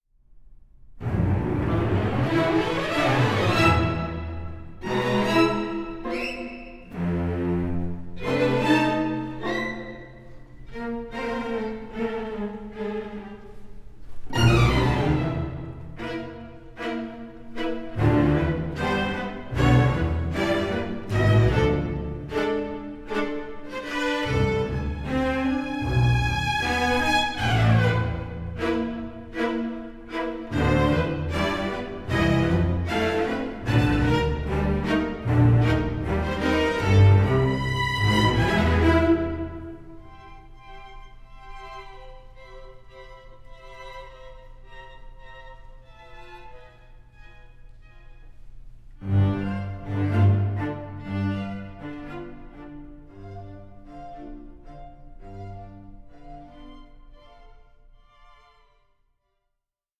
THREE PULITZER PRIZE-WINNING SYMPHONIC WORKS
is a pastoral and jubilant glorification of nature